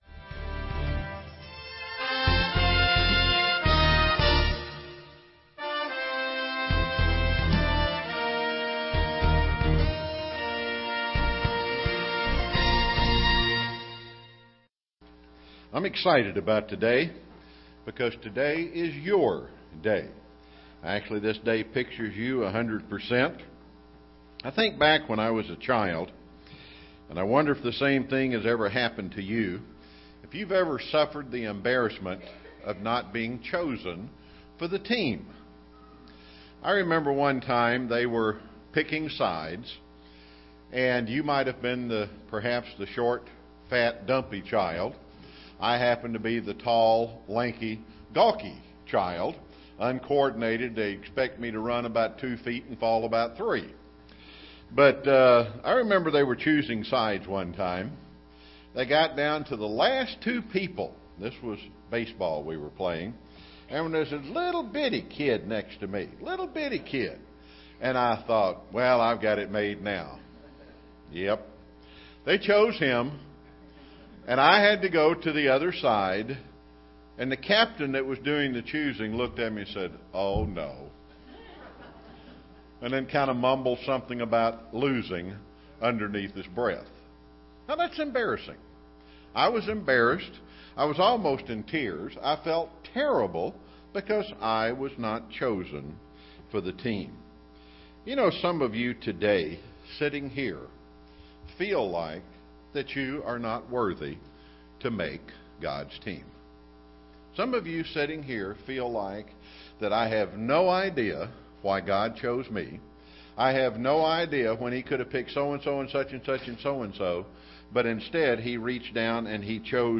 We are underdogs, but God loves underdogs. This message was given on the Feast of Pentecost.
UCG Sermon Studying the bible?